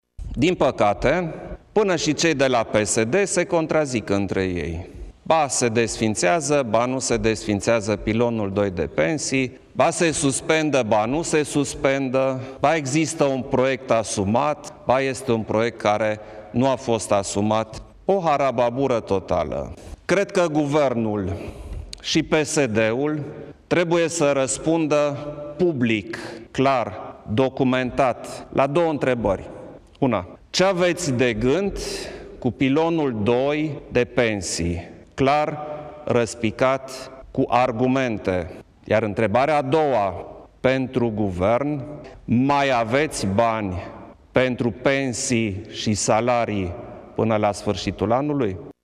Preşedintele Klaus Iohannis a solicitat Guvernului şi PSD să precizeze ‘clar, răspicat, cu argumente’ ce au de gând cu Pilonul II de pensii şi dacă mai există bani pentru salarii și pensii până la finalul anului: